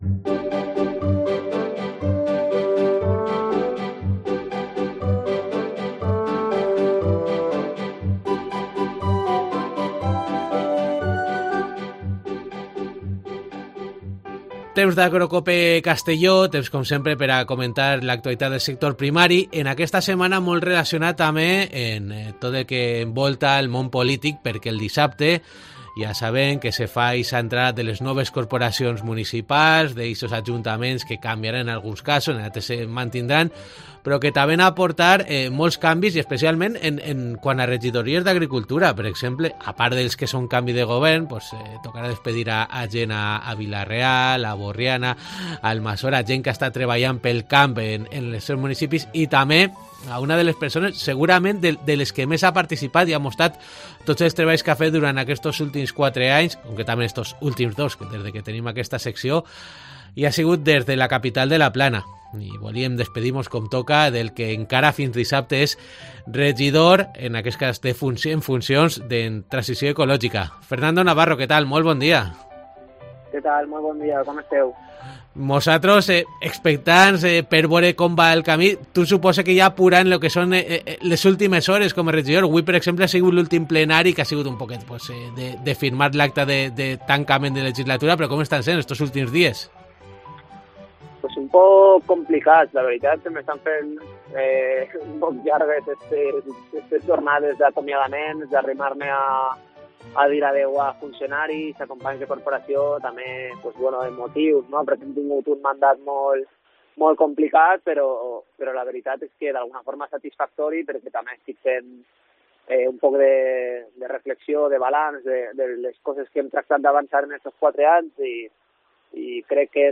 Hoy con Fernando Navarro, edil en funciones de Transición Ecológica del ayuntamiento de Castelló, y la actualidad del sector.